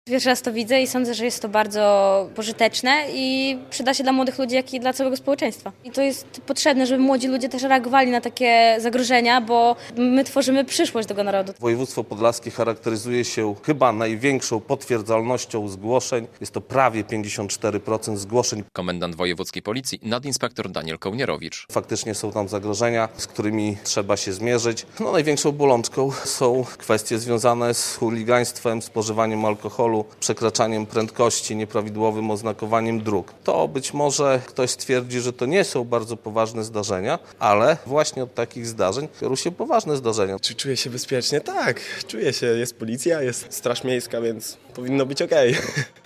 Debata o bezpieczeństwie w Białymstoku - relacja